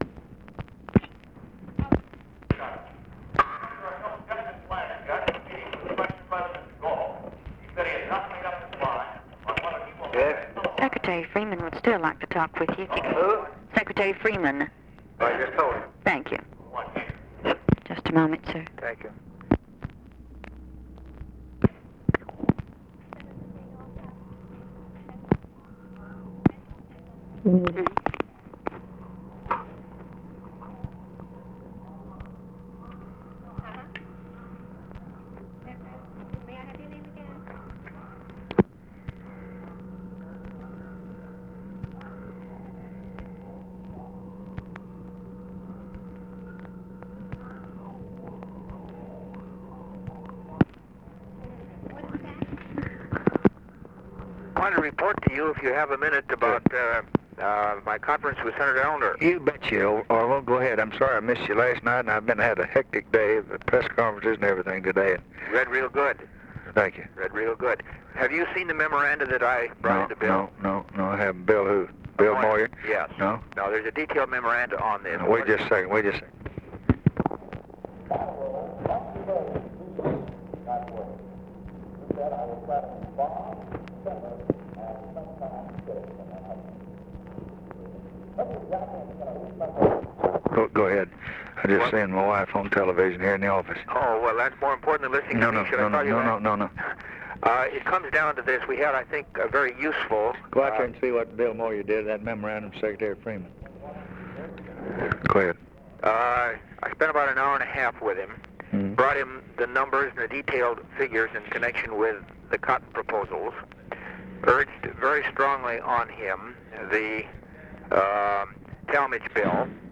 Conversation with ORVILLE FREEMAN, December 7, 1963
Secret White House Tapes